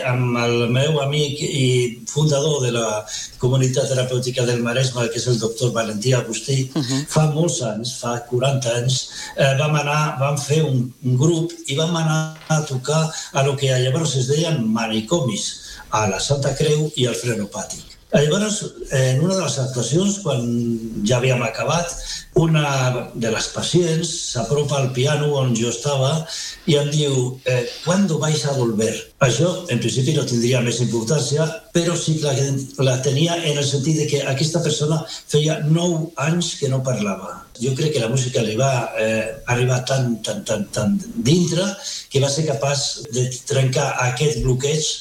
Aquest dijous hem conversat amb ell a l’Entrevista del dia del matinal de RCT, on ha recordat els orígens del projecte i una experiència especialment colpidora que exemplifica el poder de la música.